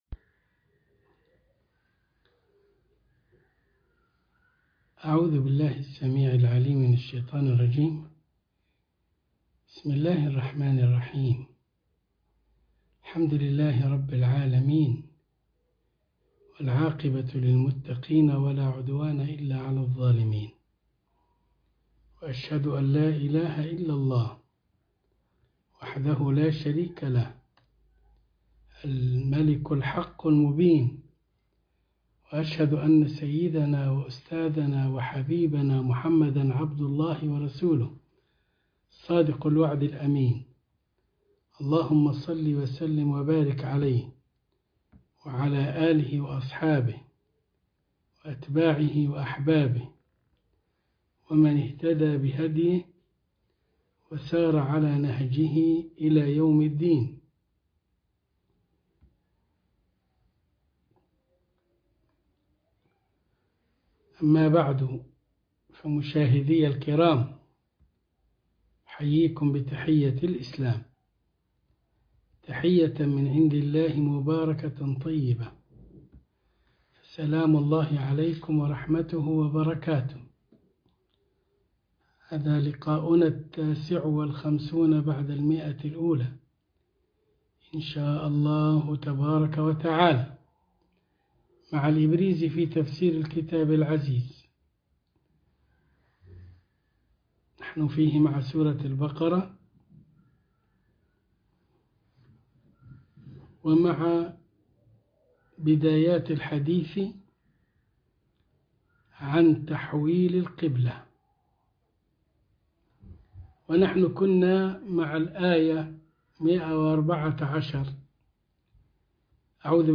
عنوان المادة الدرس ١٥٩ من الإبريز في تفسير الكتاب العزيز سورة البقرة الآية ١١٤ وما بعدها تاريخ التحميل الجمعة 3 فبراير 2023 مـ حجم المادة 31.56 ميجا بايت عدد الزيارات 314 زيارة عدد مرات الحفظ 123 مرة إستماع المادة حفظ المادة اضف تعليقك أرسل لصديق